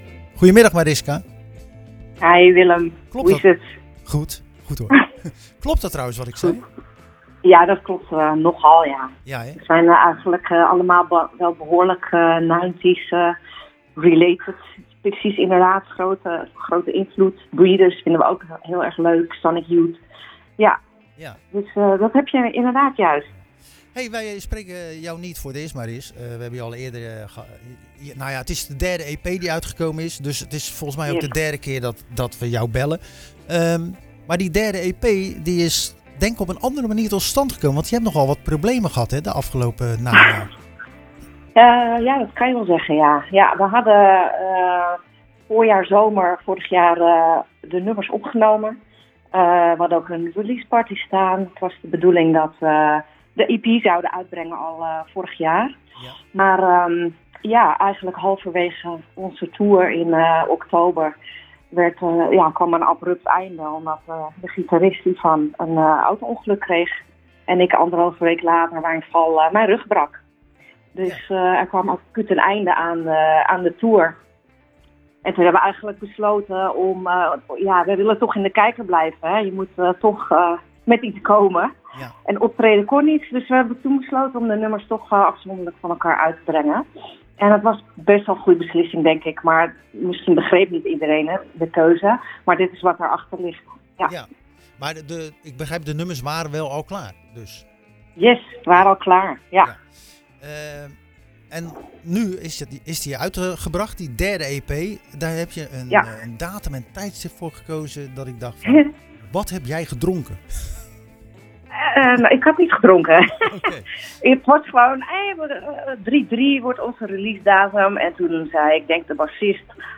We belde tijdens de Zwaardvisuitzending van 4 maart met zangeres